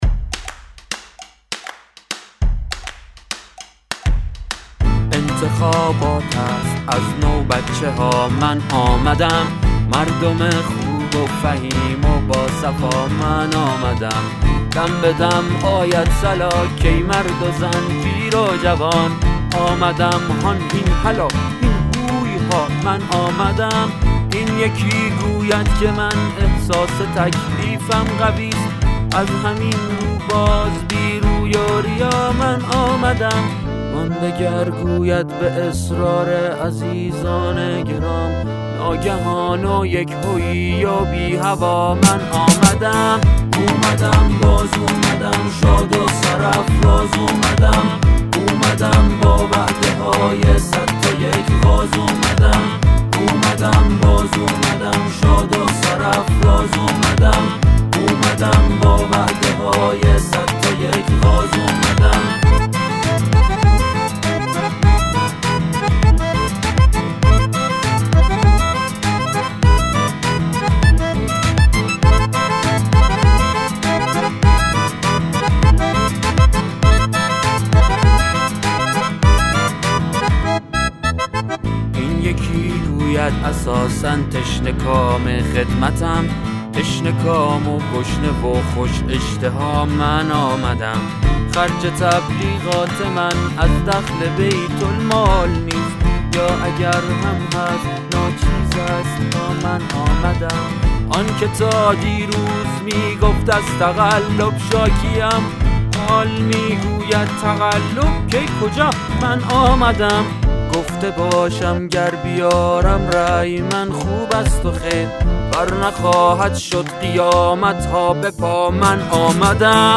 گروه موسیقی طنز